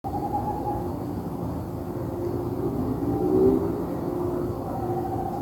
Tropical Screech Owl (Megascops choliba)
Corujinha-do-mato-211116.mp3
Life Stage: Adult
Country: Brazil
Condition: Wild
Certainty: Recorded vocal